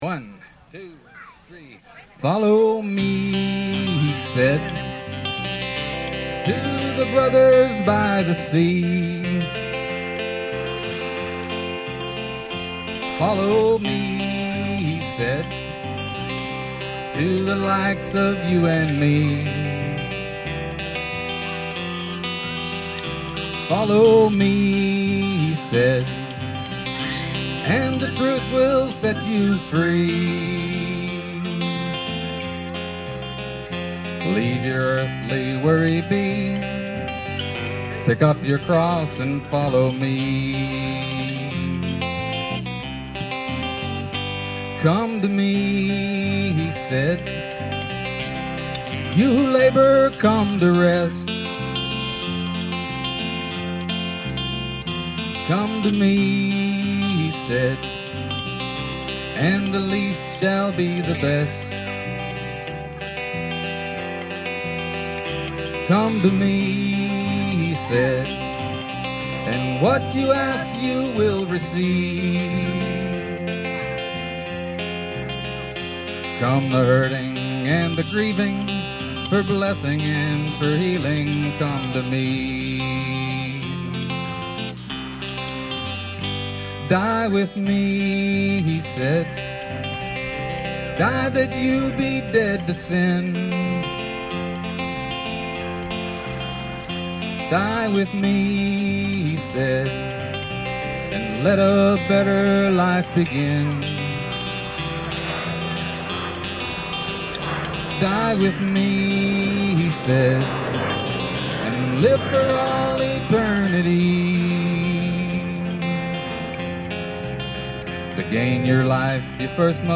Follow Me (live at the BAM Picnic)    Download MP3